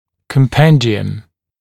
[kəm’pendɪəm][кэм’пэндиэм]конспект; резюме, краткое изложение